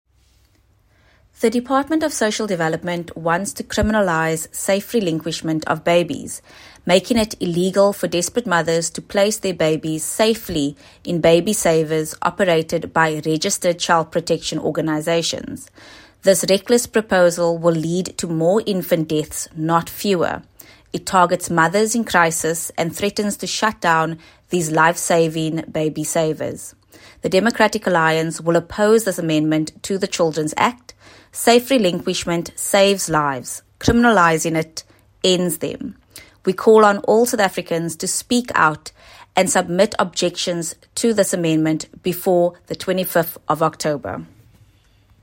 Soundbite by Alexandra Abrahams MP.